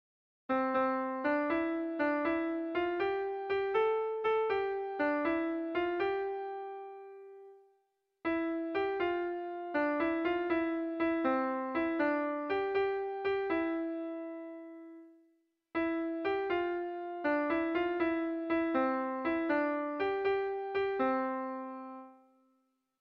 Irrizkoa
ABDE